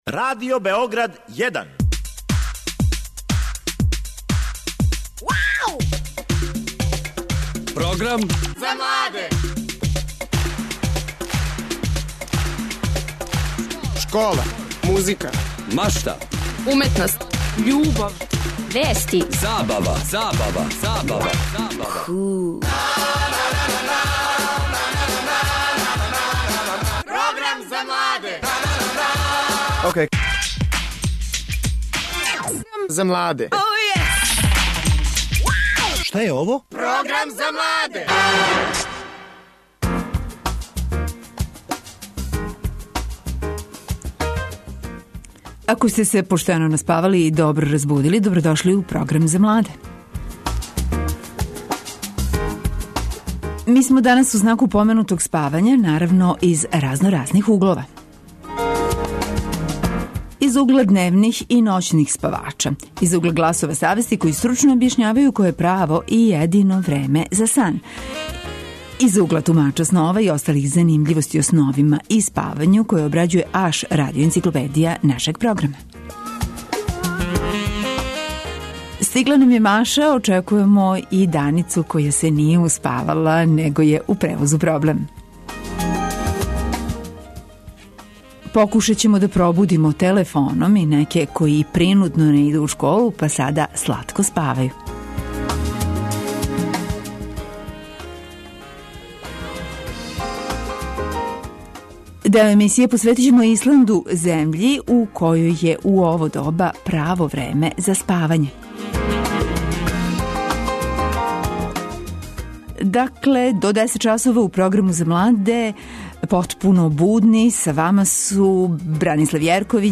Део емисије је посвећен и Исланду. Од наше гошће чућемо како сањају људи на Исланду, када су поларне ноћи, када мрака готово и да нема.